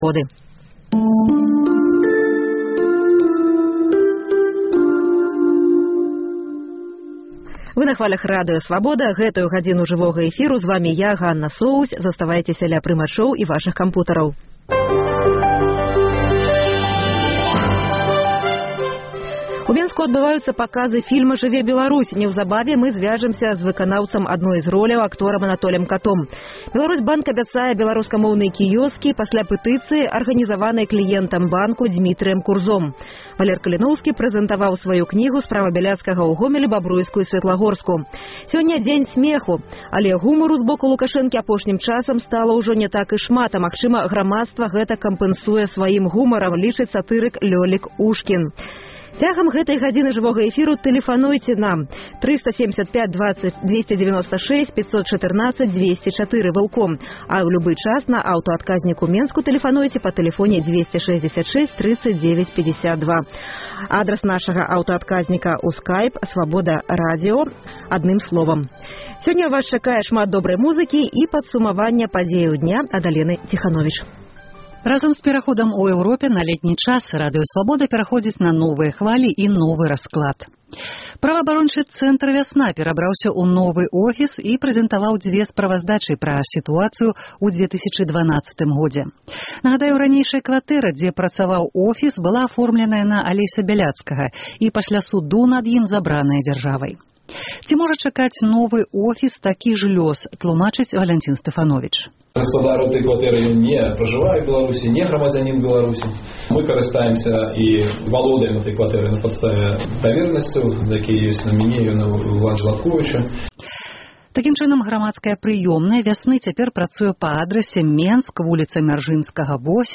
Ад 20-й да 21-й гадзіны ў жывым эфіры «Свабоды»